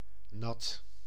Ääntäminen
IPA: /nɑt/